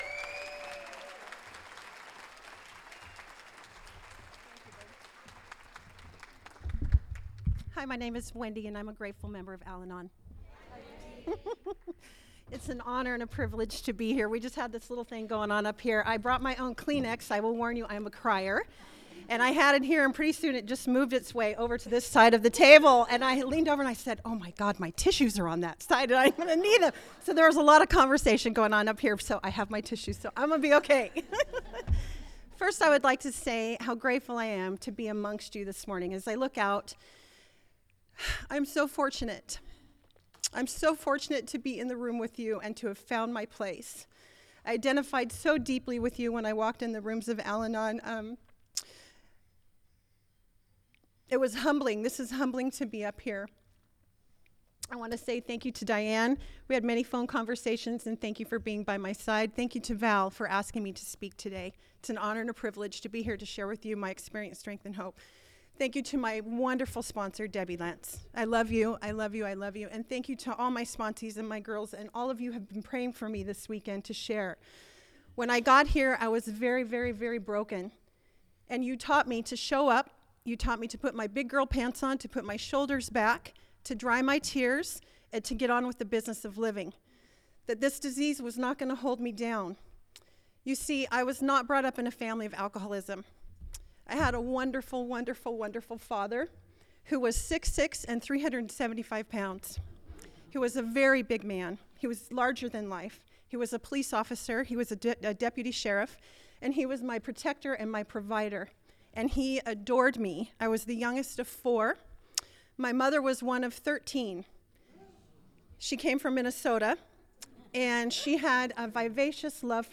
46th So Cal Al-Anon Convention